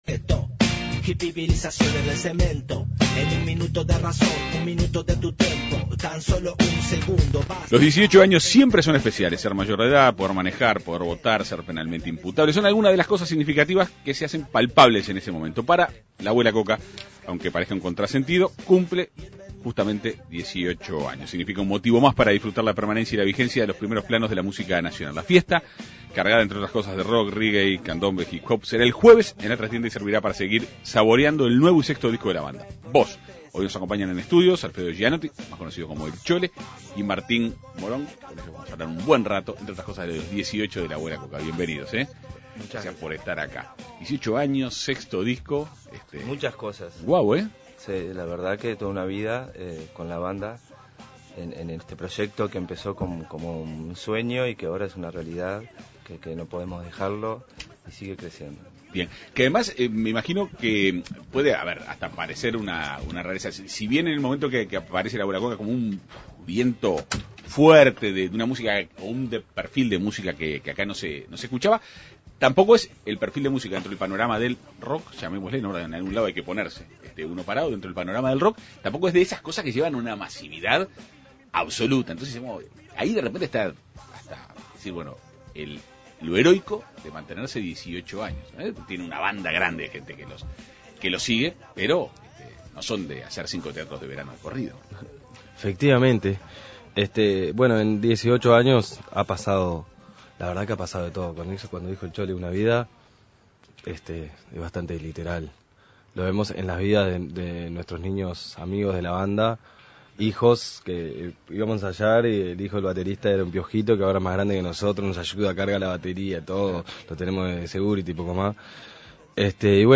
Dos de sus músicos estuvieron en la Segunda Mañana de En Perspectiva.